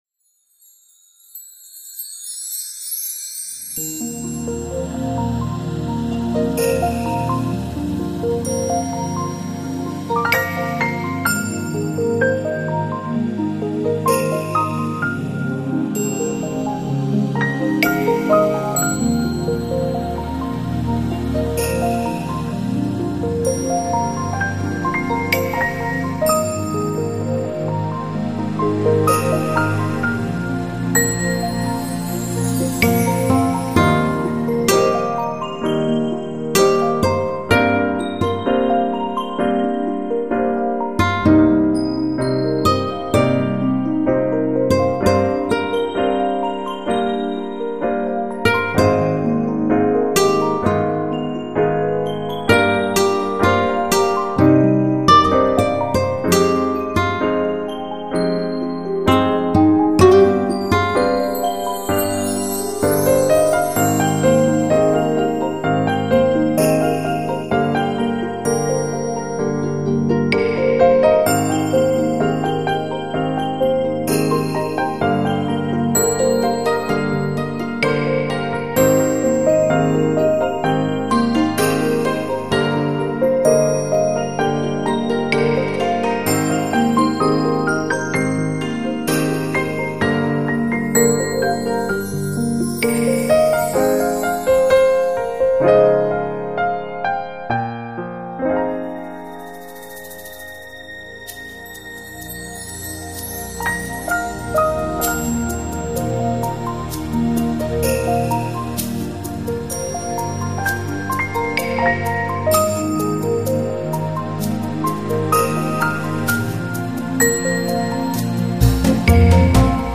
很治愈的一首音乐，很休闲放松的音乐！